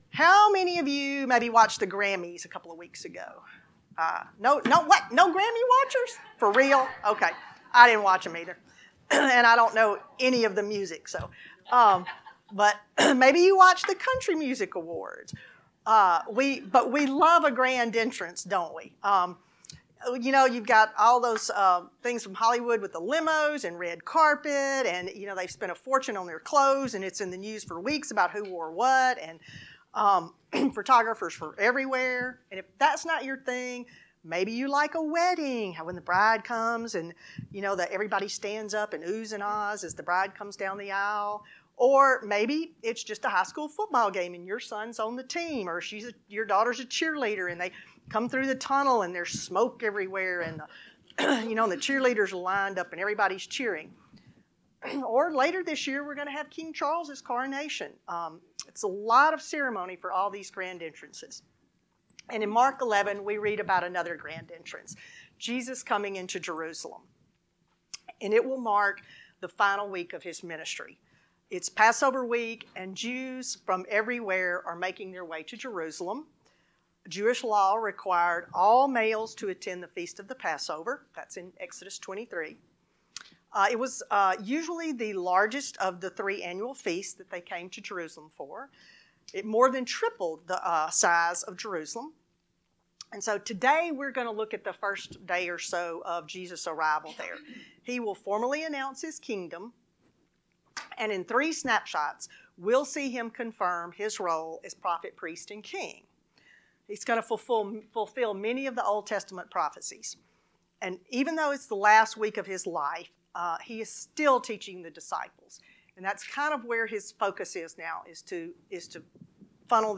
Lesson 16